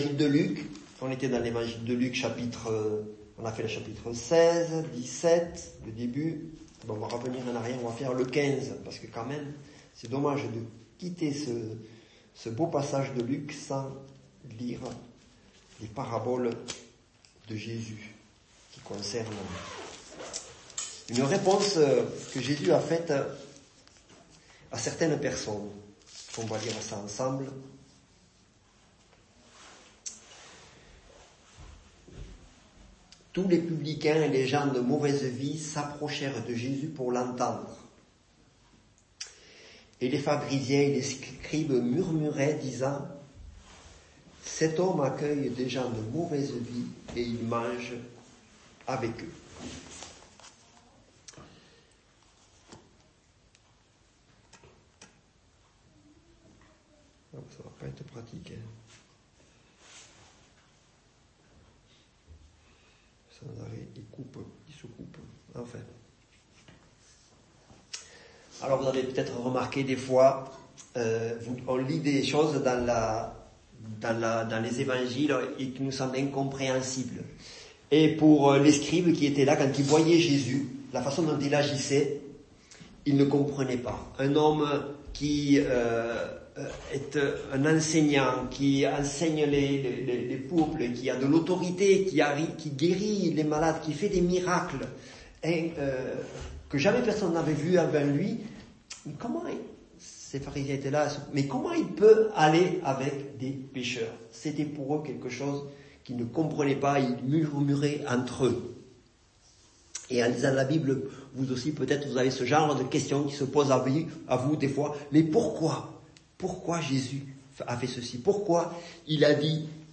Culte du dimanche 13 octobre 2024 - EPEF